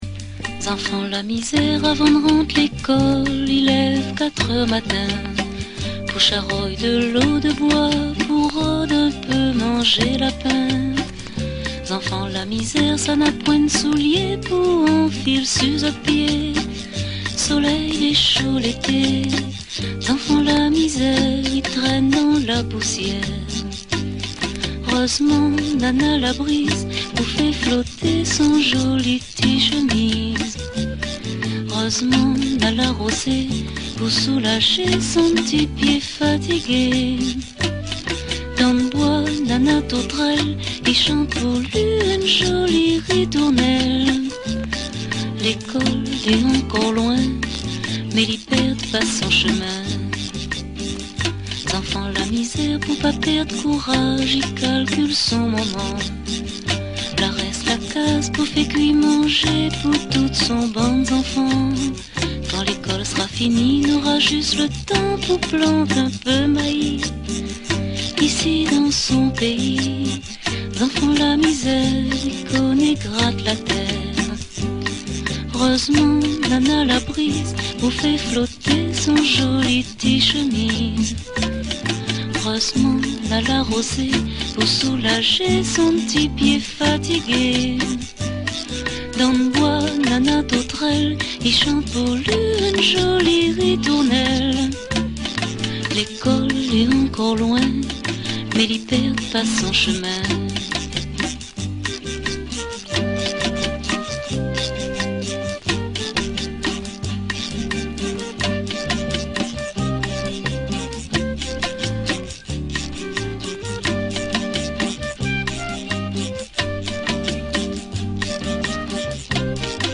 Interview exclusive de Jacqueline farreyrol par téléphone